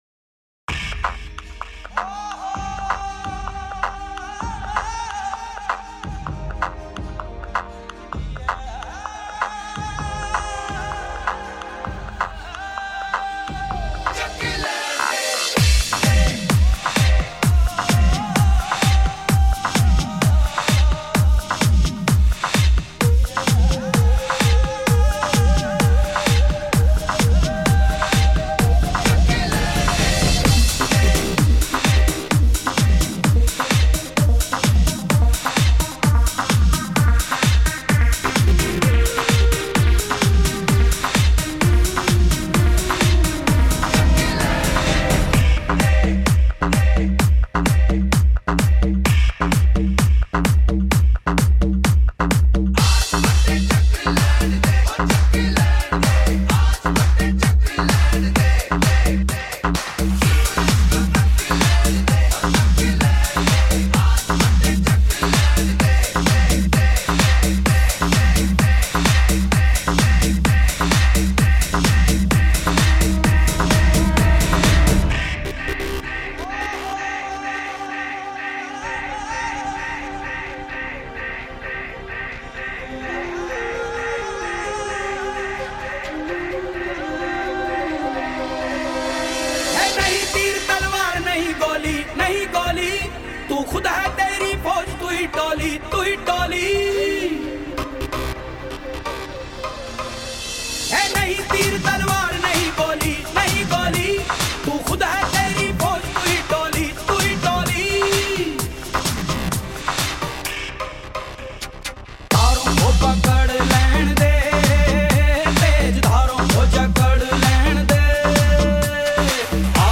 2. Bollywood MP3 Songs